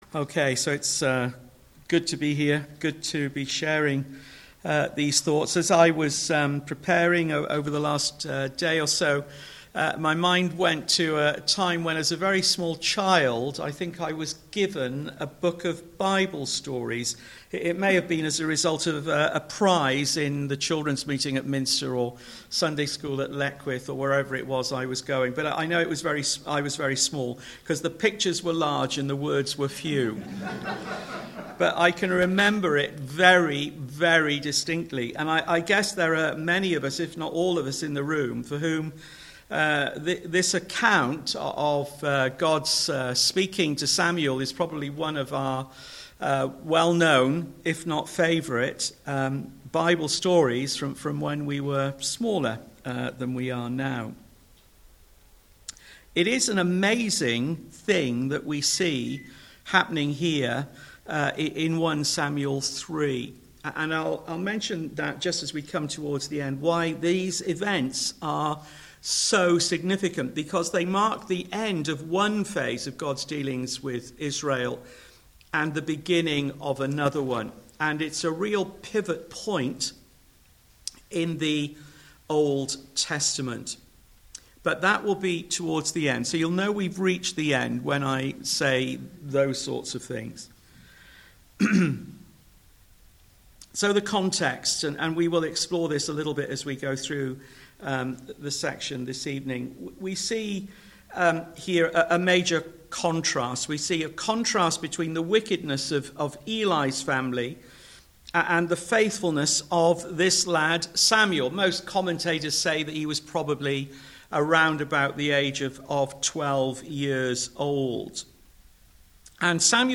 1 Samuel 3 Service Type: Sunday Evening Topics